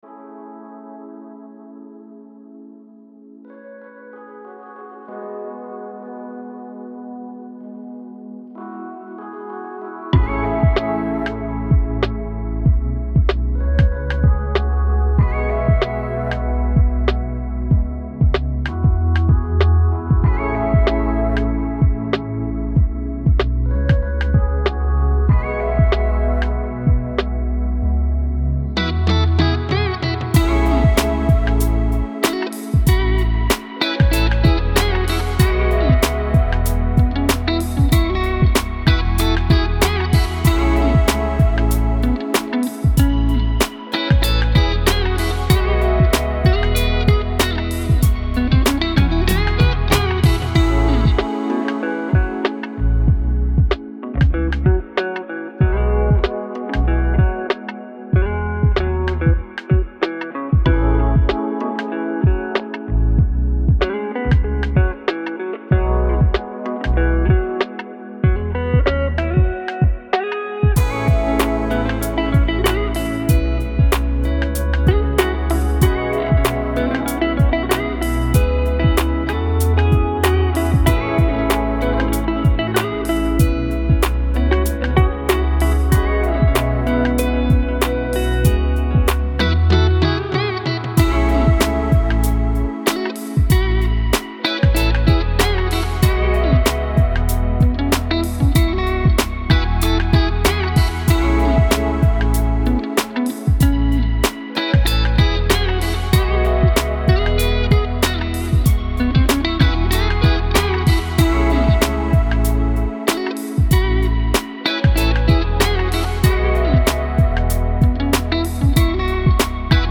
موسیقی بی کلام امید بخش